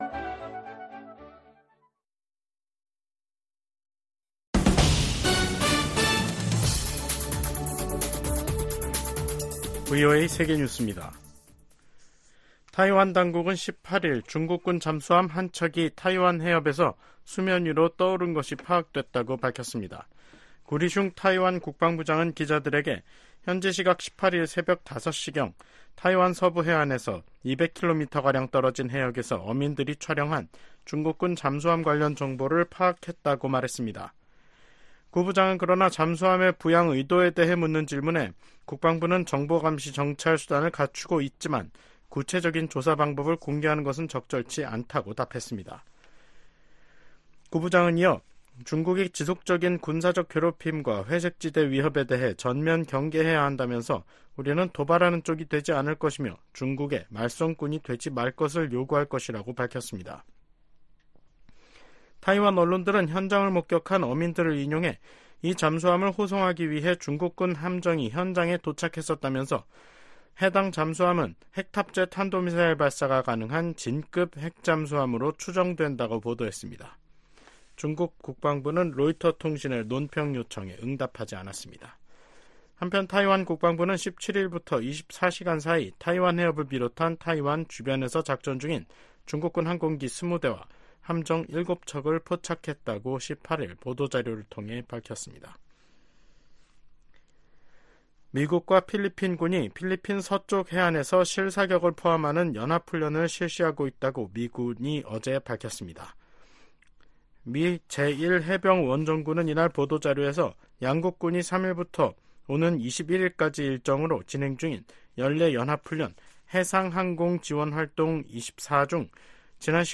VOA 한국어 간판 뉴스 프로그램 '뉴스 투데이', 2024년 6월 18일 3부 방송입니다. 미 국무부는 블라디미르 푸틴 러시아 대통령의 방북과 두 나라의 밀착이 미국뿐 아니라 국제사회가 우려하는 사안이라고 지적했습니다. 미국의 전문가들은 러시아가 푸틴 대통령의 방북을 통해 탄약 등 추가 무기 지원을 모색하고 북한은 식량과 경제 지원, 첨단 군사기술을 얻으려 할 것이라고 분석했습니다.